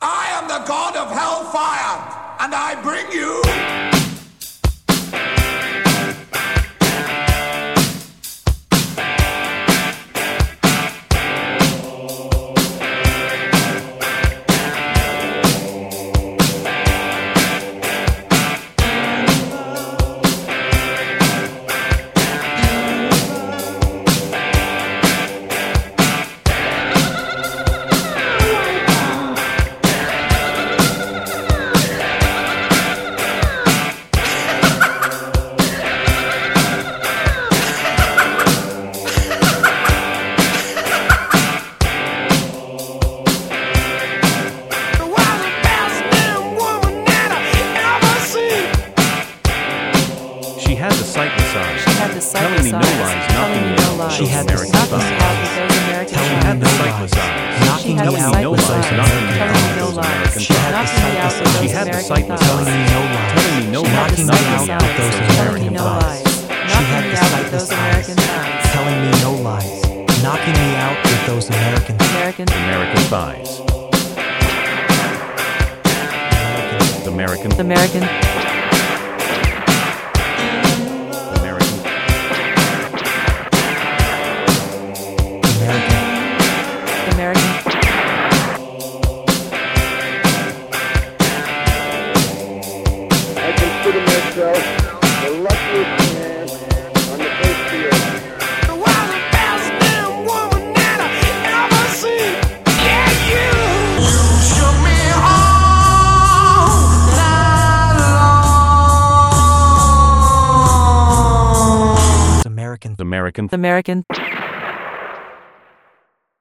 AI generated voices reciting some lyrics